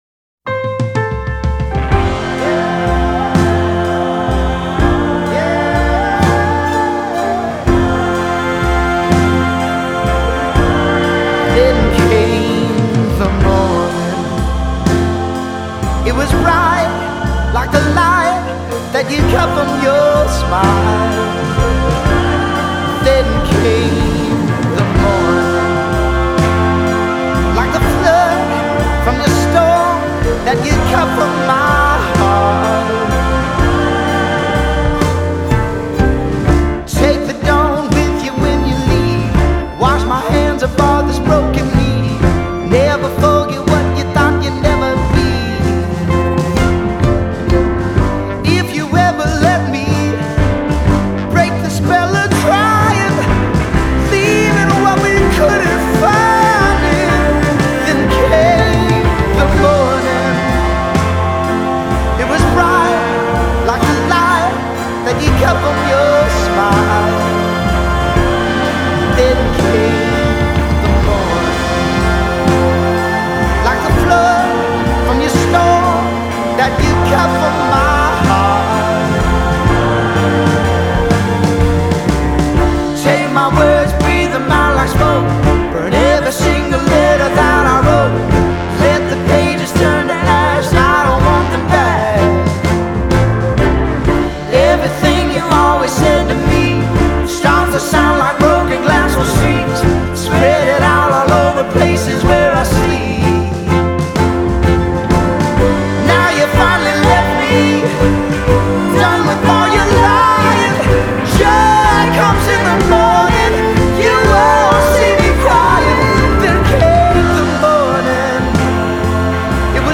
expansive, lush, Folk Rock
soulful cry
a march through atmospherics